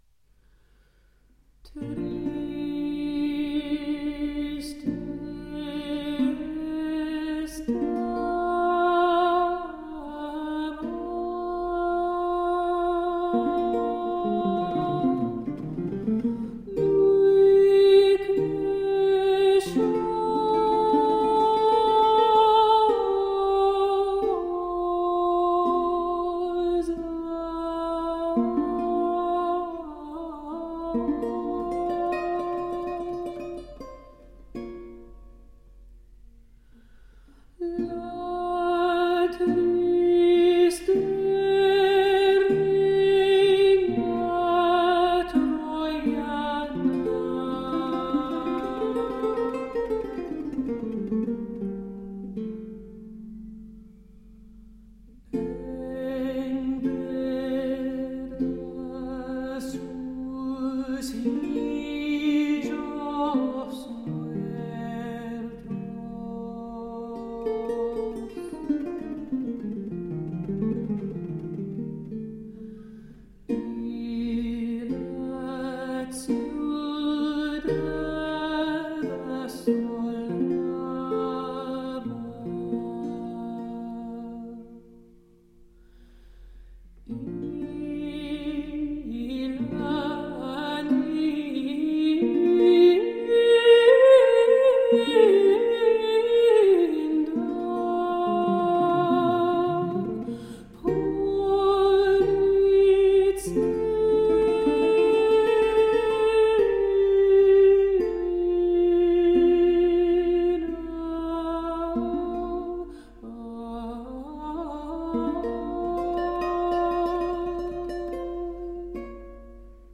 Renaissance songs.